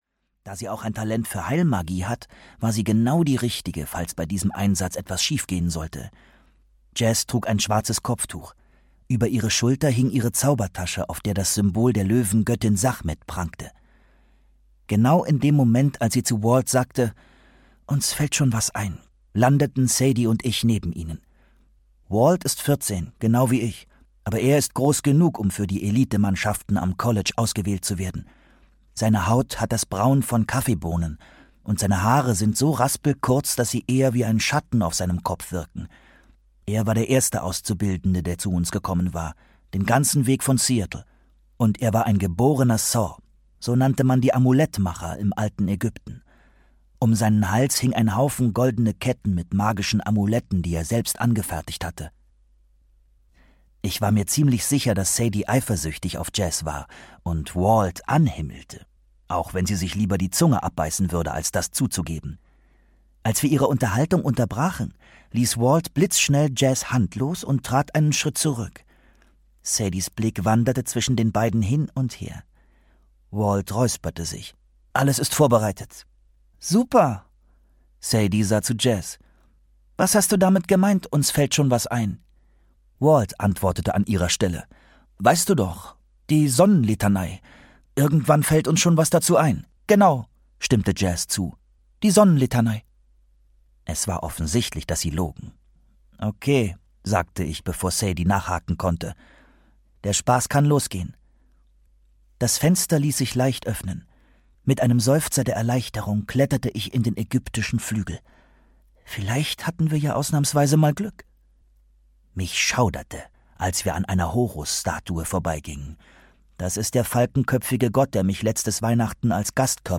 Hörbuch Die Kane-Chroniken 2: Der Feuerthron, Rick Riordan.